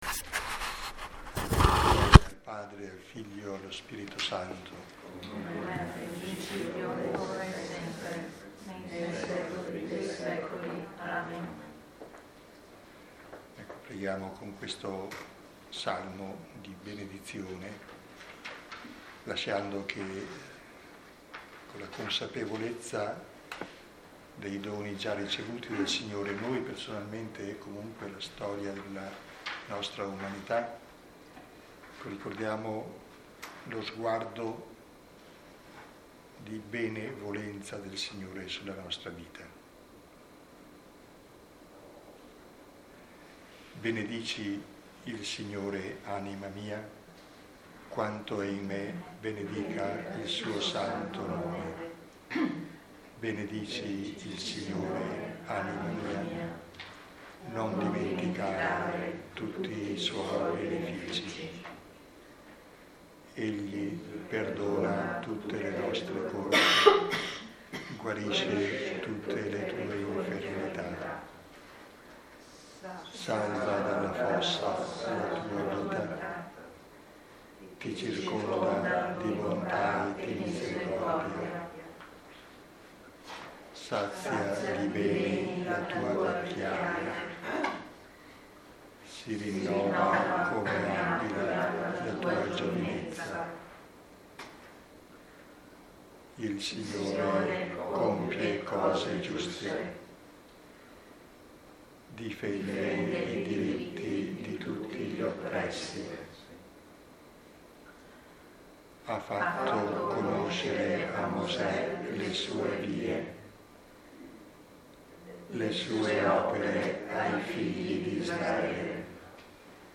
Lectio 5 – 16 febbraio 2020 – Antonianum – Padova